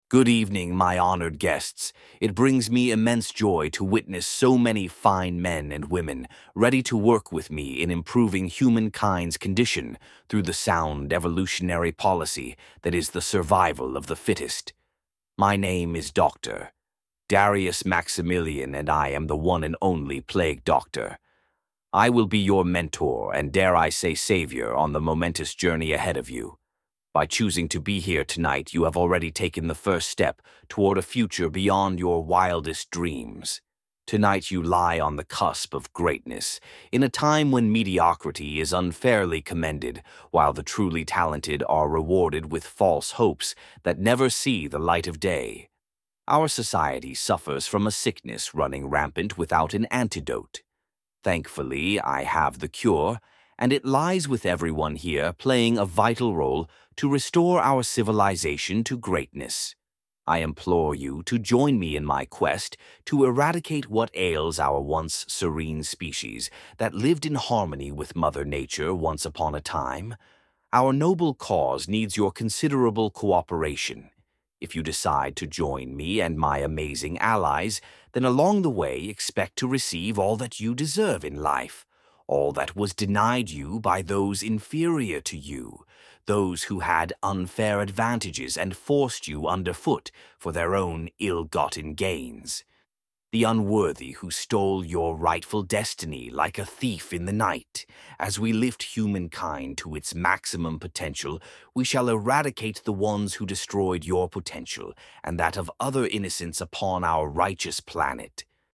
Plague-Doctors-Dark-Sermon.mp3